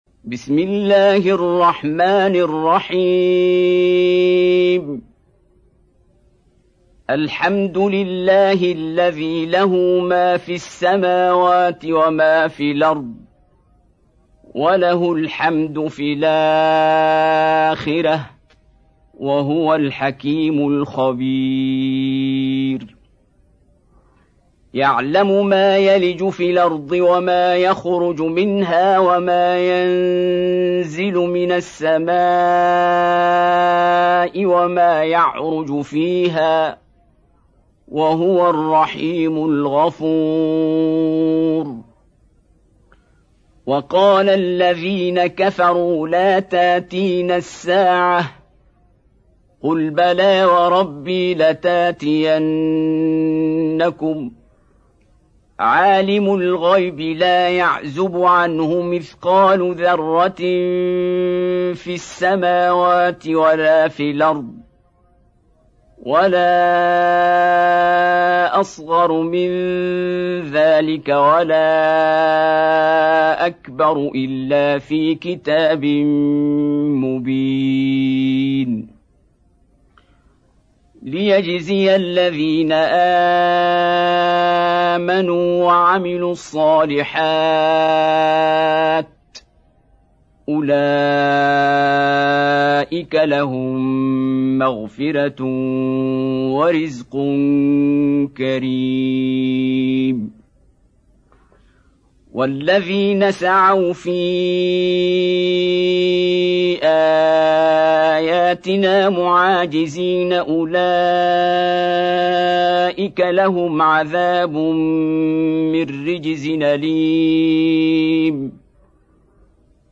Surah Al saba Beautiful Recitation MP3 Download By Qari Abdul Basit in best audio quality.